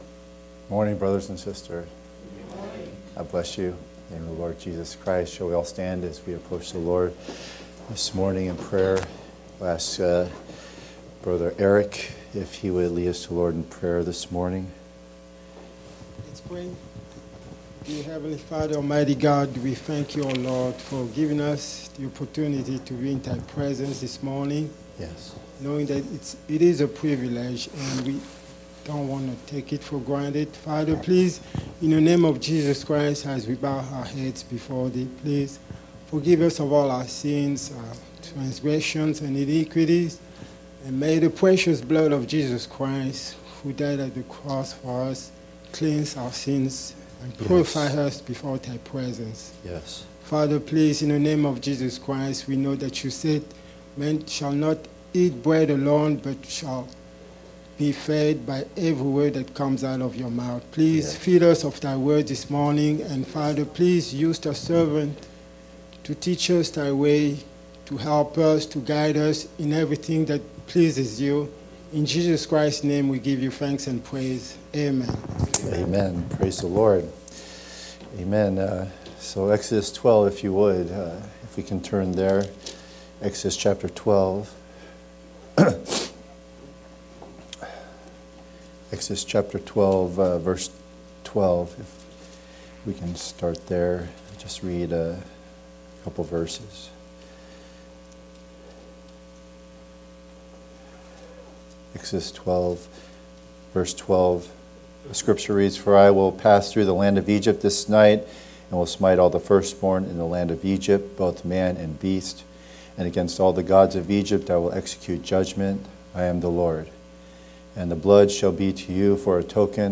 The Token – Part 3 (Sunday school)